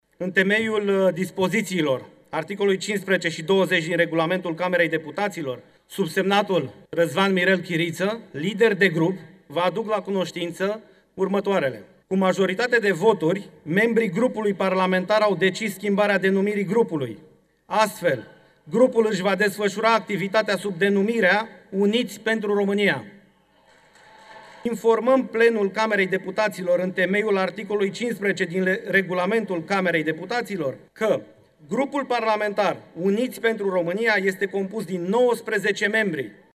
Netulburat, Mirel Chiriță a venit apoi la tribuna Camerei Deputaților, unde a anunțat care sunt liderii noului grup parlamentar format și că cinci parlamentari au aderat deja la el.